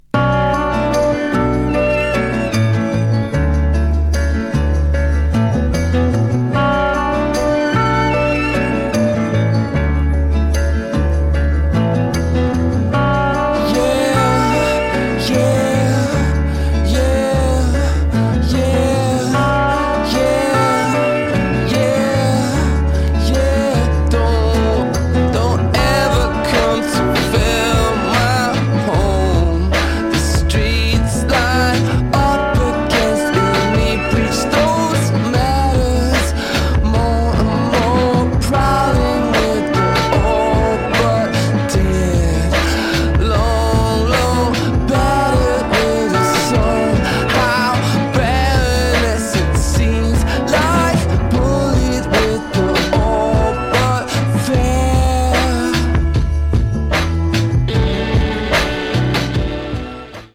New Release Indie Rock New Wave / Rock